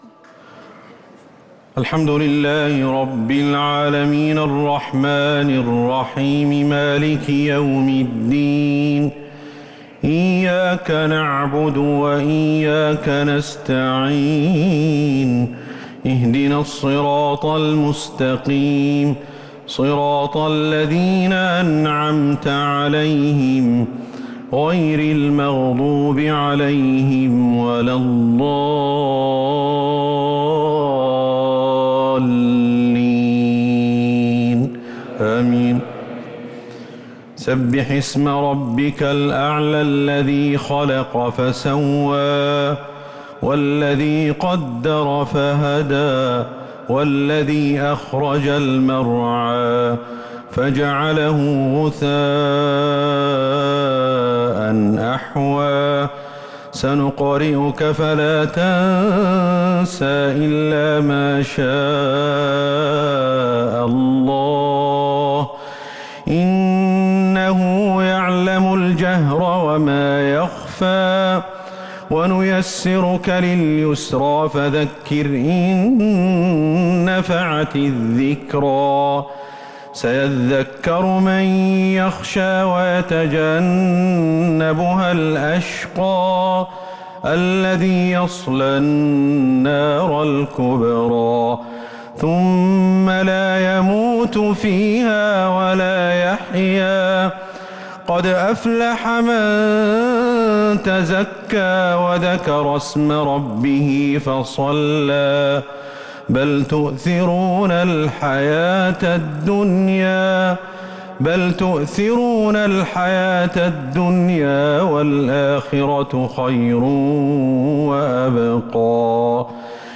الشفع و الوتر ليلة 15 رمضان 1443هـ | Witr 15 st night Ramadan 1443H > تراويح الحرم النبوي عام 1443 🕌 > التراويح - تلاوات الحرمين